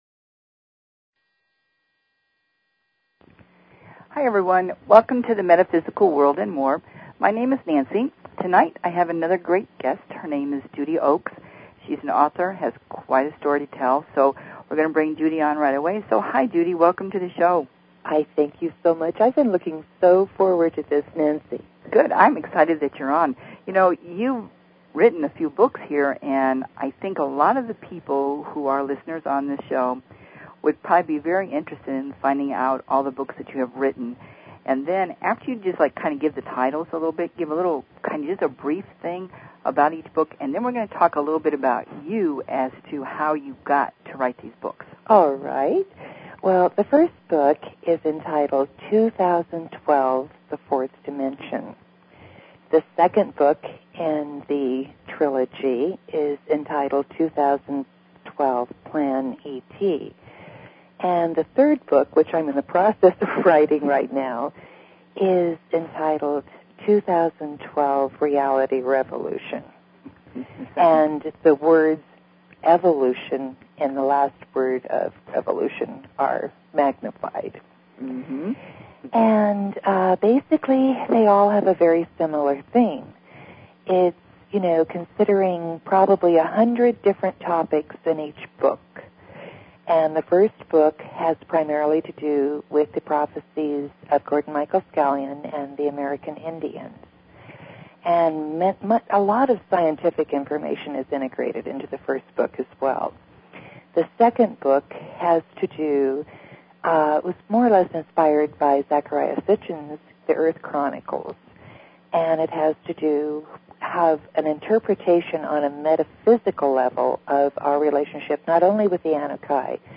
Talk Show Episode, Audio Podcast, Nancys_Metaphysical_World_and_More and Courtesy of BBS Radio on , show guests , about , categorized as